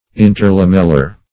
Search Result for " interlamellar" : The Collaborative International Dictionary of English v.0.48: Interlamellar \In`ter*lam"el*lar\, Interlaminar \In`ter*lam"i*nar\, a. (Anat.) Between lammell[ae] or lamin[ae]; as, interlamellar spaces.